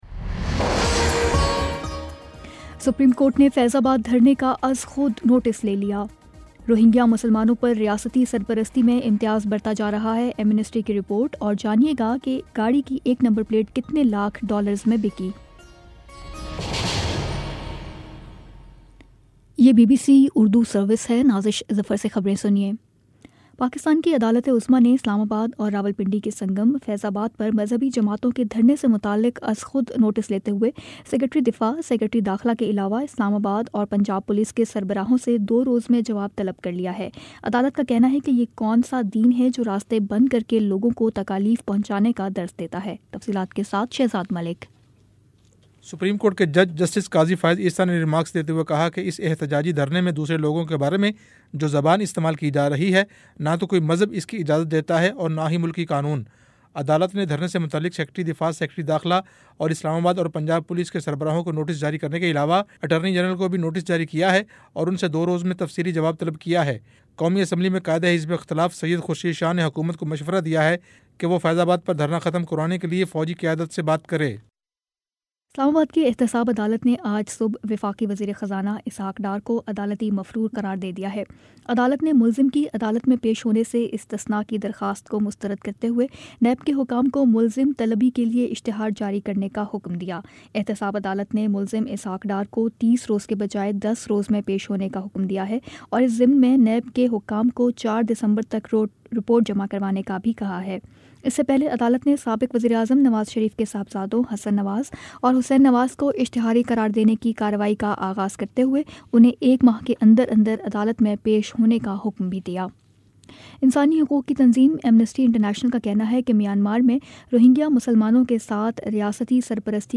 نومبر21 : شام سات بجے کا نیوز بُلیٹن